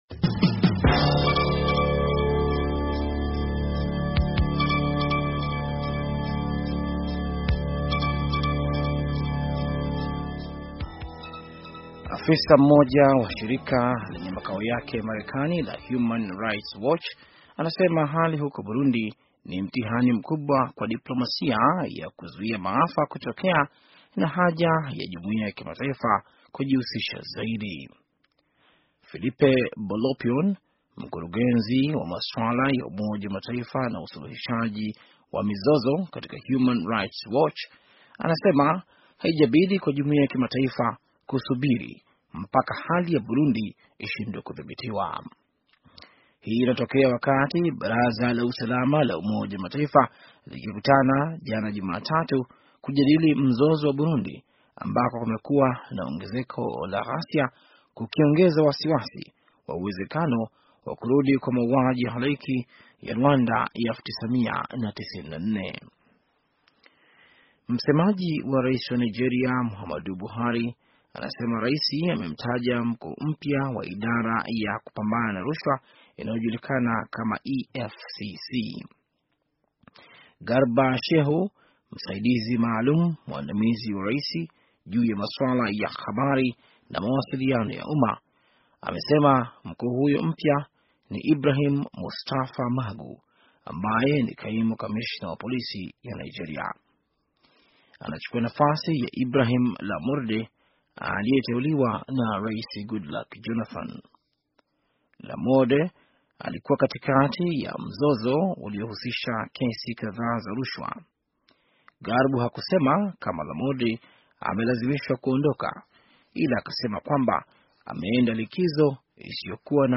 Taarifa ya habari - 5:33